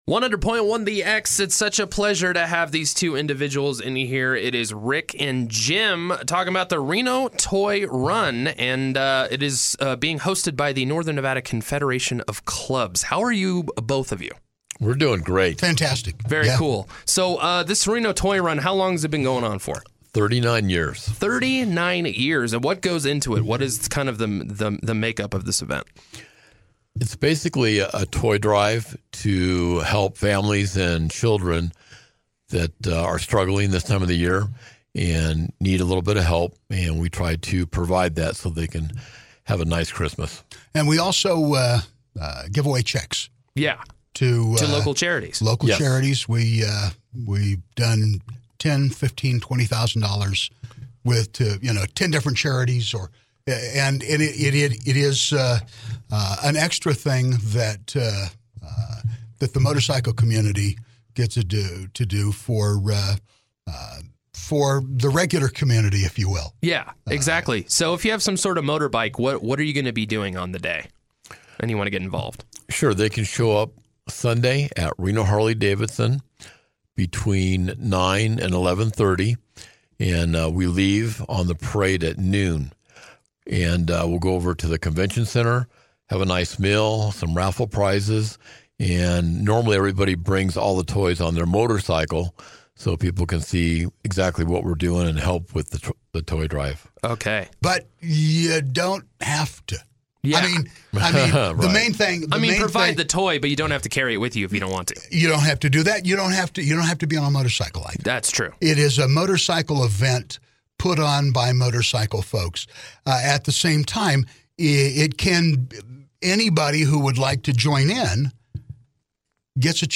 Interview: Reno Toy Run